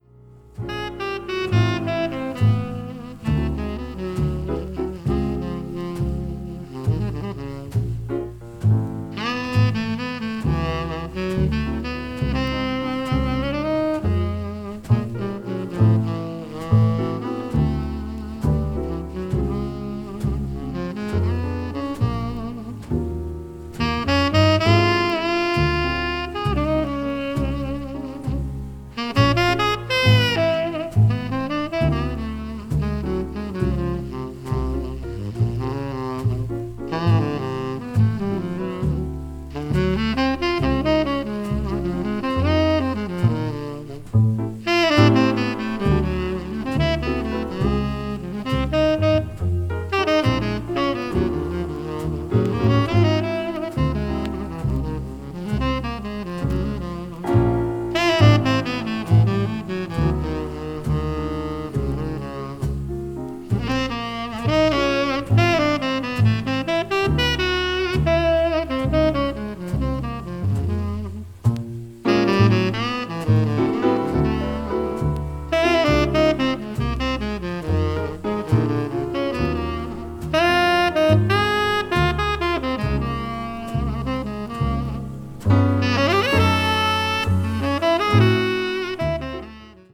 recordings from a Los Angeles studio session
sextet
trumpet
trombone
piano
bass
bop   hard bop   modern jazz